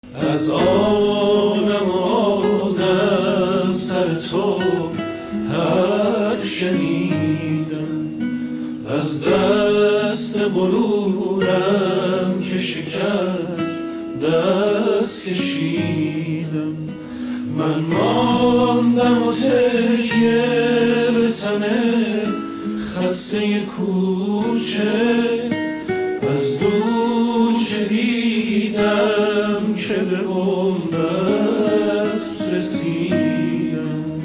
آهنگی پر احساس و شنیدنی